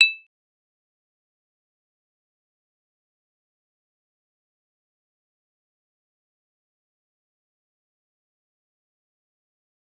G_Kalimba-E8-mf.wav